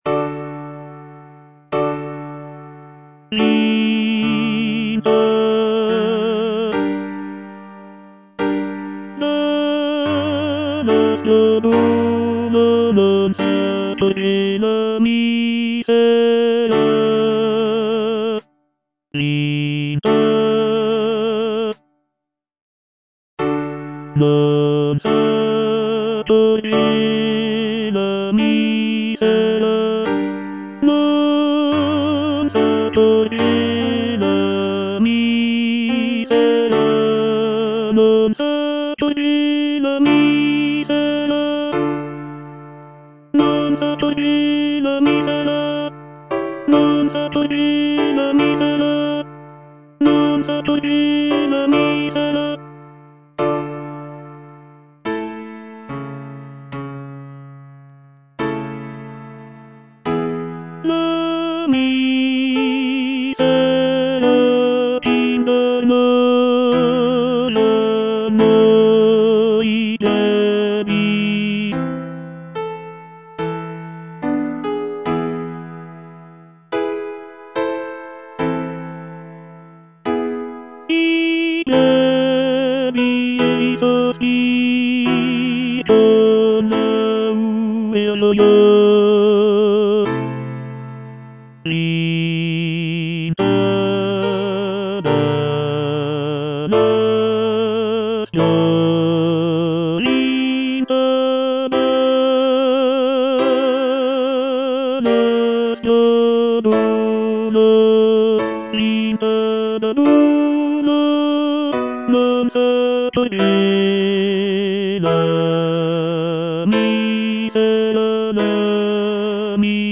C. MONTEVERDI - LAMENTO D'ARIANNA (VERSION 2) Coro III: Vinta da l'aspro duolo - Tenor (Voice with metronome) Ads stop: auto-stop
The music is characterized by its expressive melodies and poignant harmonies, making it a powerful and moving example of early Baroque vocal music.